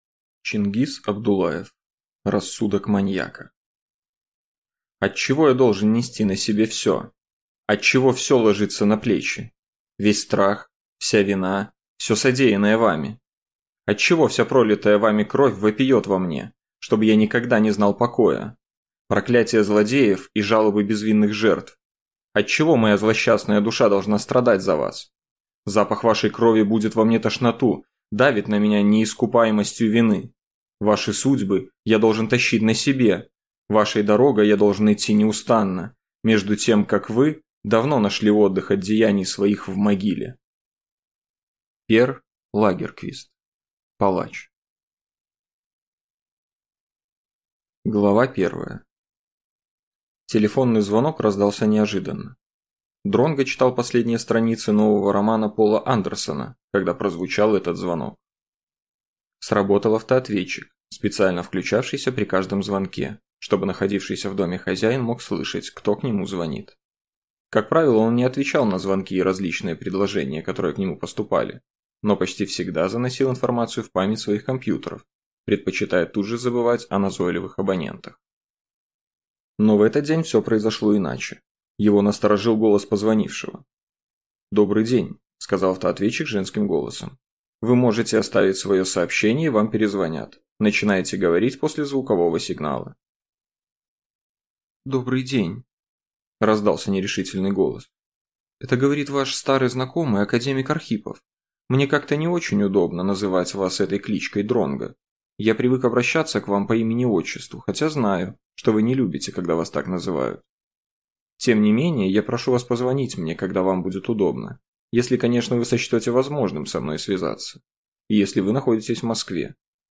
Аудиокнига Рассудок маньяка | Библиотека аудиокниг